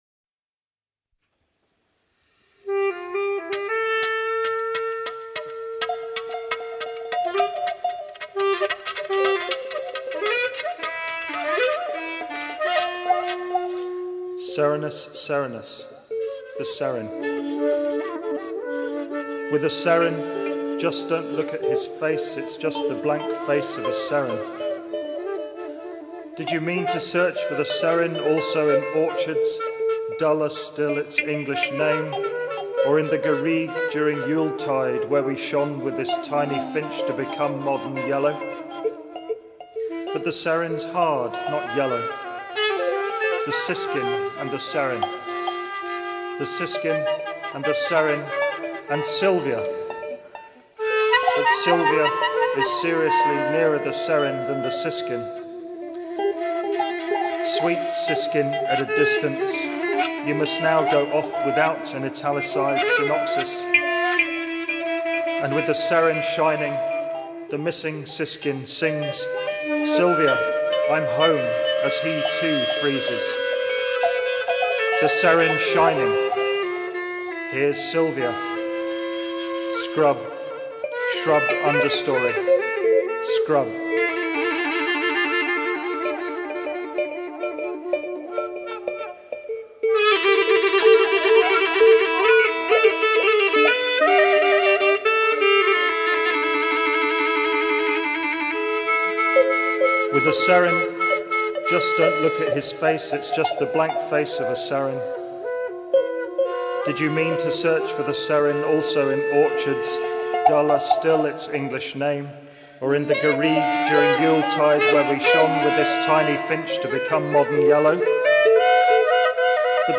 spoken by the poet
shakuhachi
electric guitar
clarinet